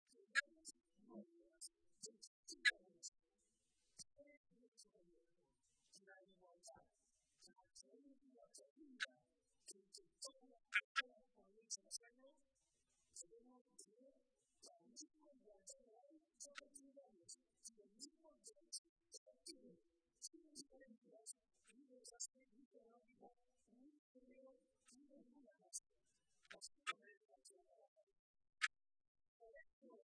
El presidente José María Barreda asistía este domingo a la tradicional comida de Navidad del PSOE de Toledo, en la que participaron unos 1.600 militantes y simpatizantes de toda la provincia.